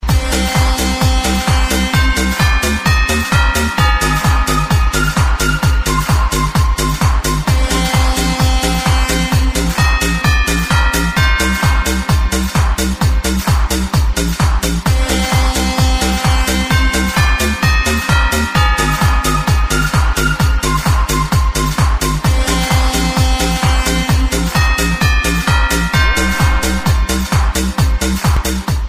Download loud cut for ringtone